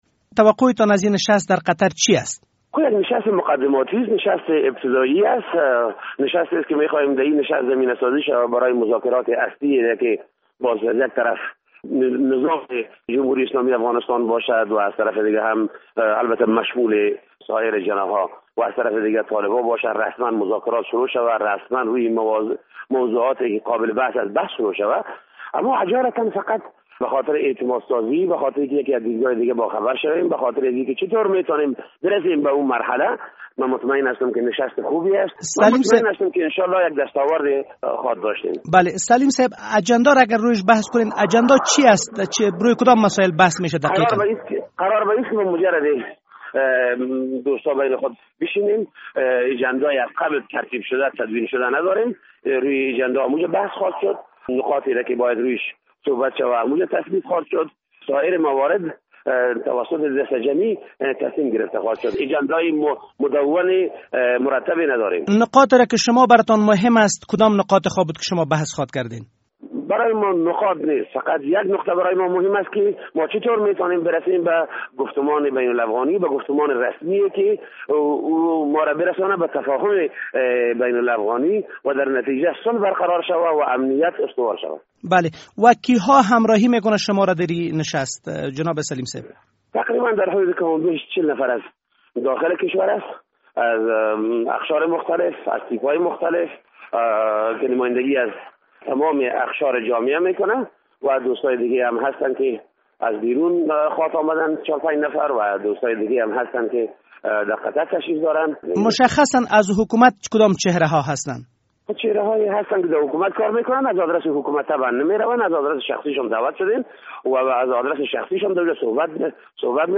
مصاحبه - صدا
عطاالرحمان سلیم معاون شورای عالی صلح افغانستان